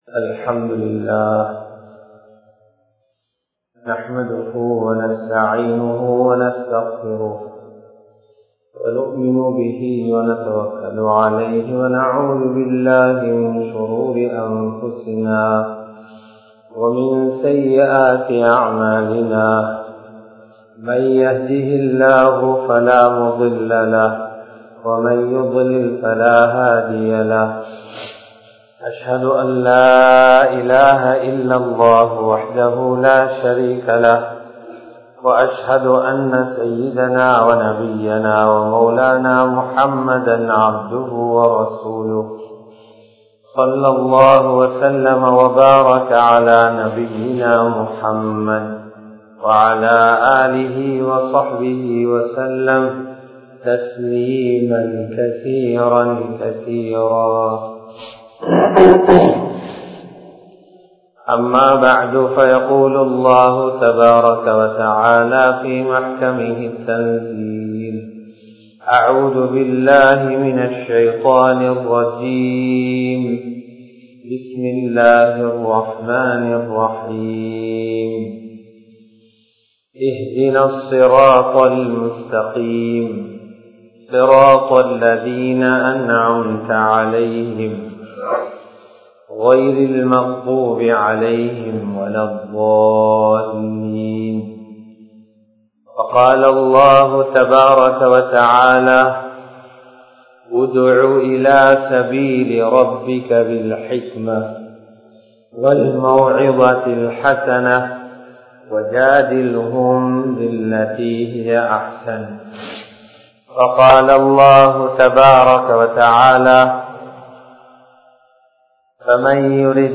தஃவத்தும் நற்குணமும் | Audio Bayans | All Ceylon Muslim Youth Community | Addalaichenai
Dehiwela, Muhideen (Markaz) Jumua Masjith